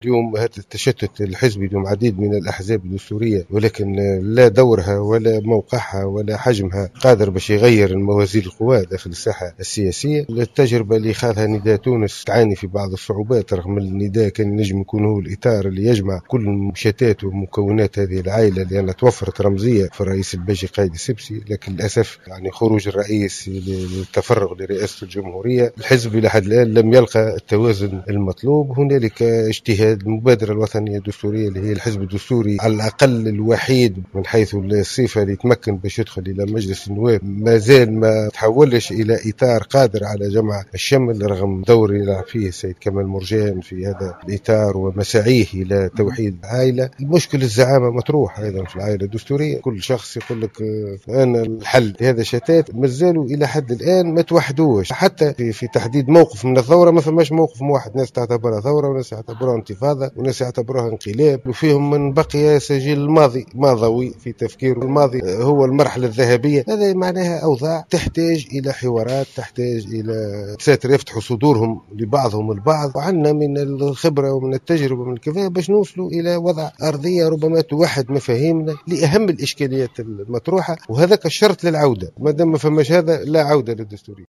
وقال الغرياني في تصريح اليوم الأحد في ملتقى لهياكل المنظمة الشبابية لحزب المبادرة الوطنية الدستورية بنابل، إن هناك صعوبات أمام محاولات لم شمل العائلة الدستورية، رغم المجهودات التي يقوم بها زعيم حزب المبادرة كمال مرجان.